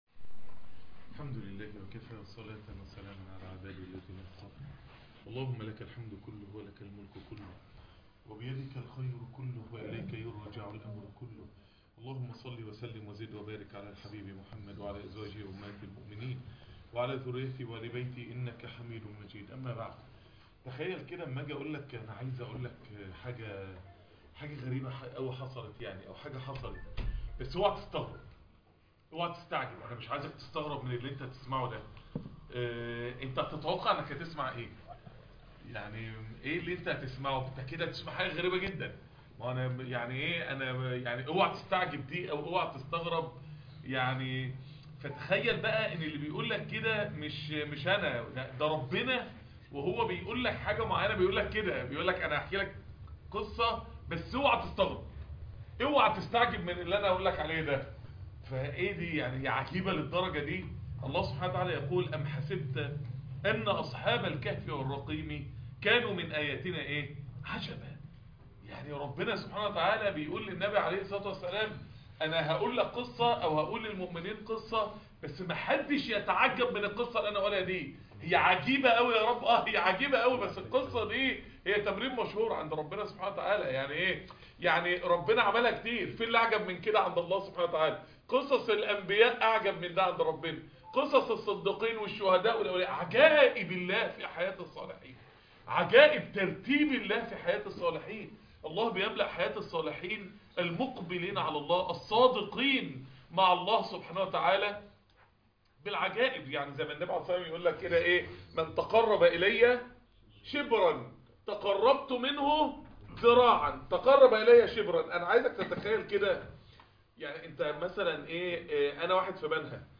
عجائب معاملة الله للصالحين .. محاضرة نادرة